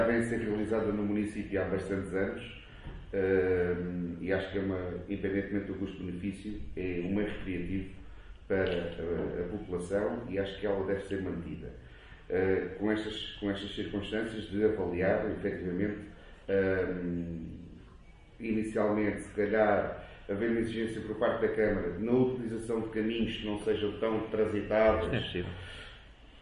O Raid foi tema em discussão na última reunião de Câmara Municipal de Gavião.
ÁUDIO | VEREADOR DO PSD, VITOR FILIPE: